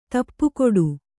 ♪ tappu koḍu